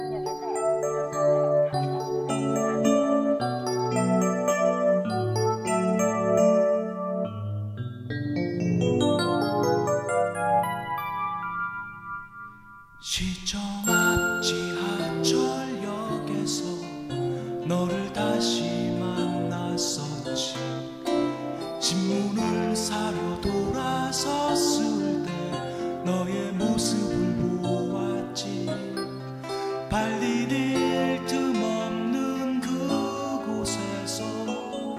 Жанр: Фолк-рок / Рок